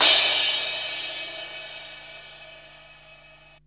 CRASH04   -R.wav